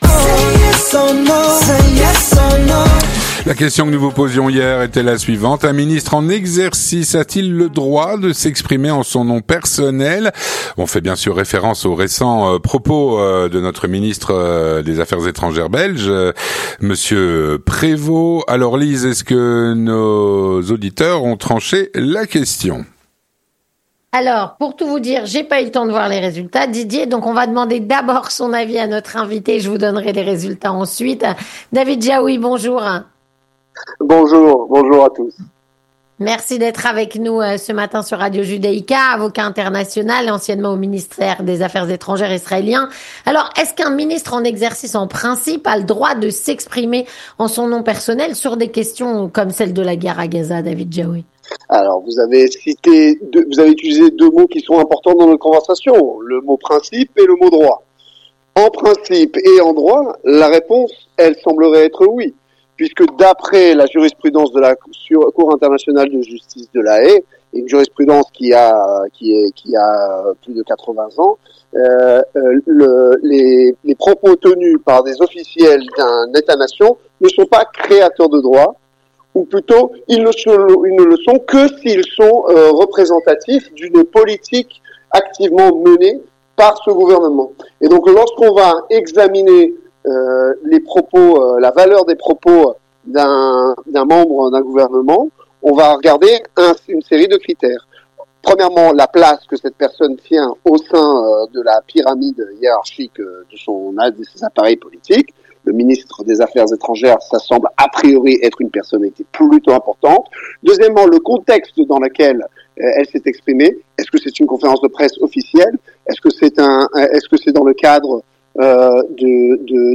Avocat international, répond à "La Question Du Jour".